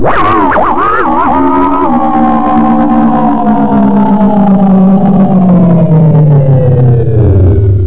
he_attack3.wav